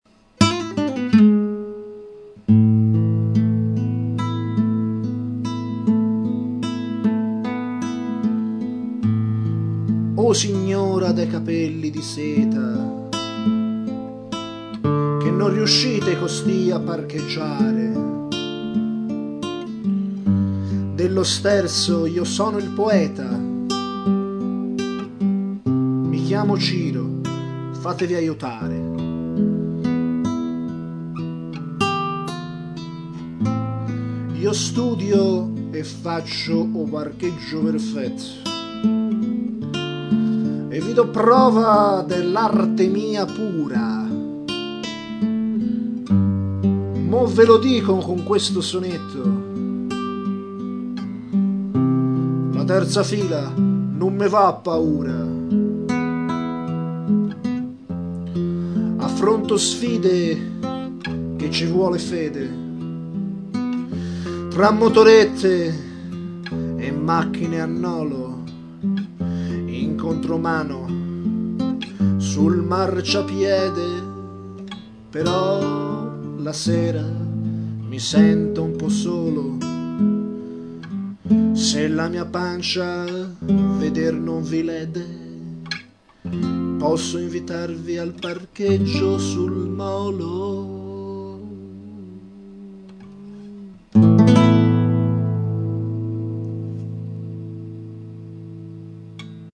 un sonetto recitato su un delicato arpeggio